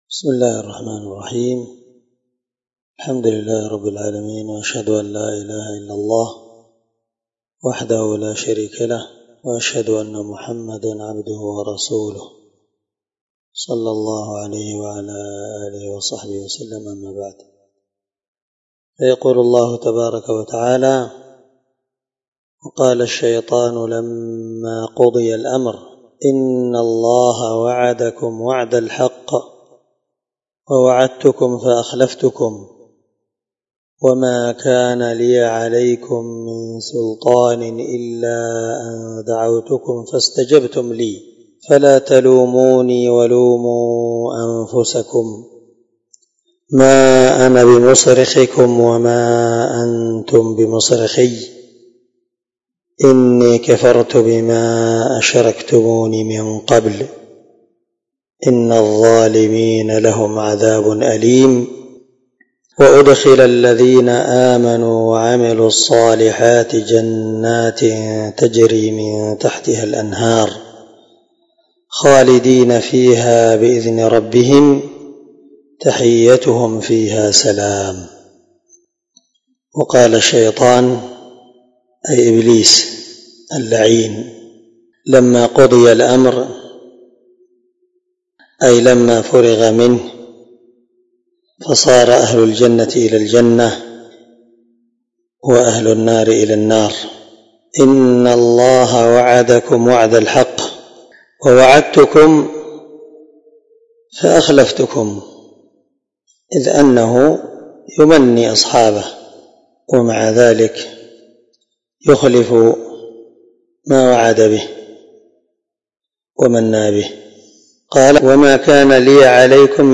701الدرس 8 تفسير آية (22-23) من سورة إبراهيم من تفسير القرآن الكريم مع قراءة لتفسير السعدي
دار الحديث- المَحاوِلة- الصبيحة.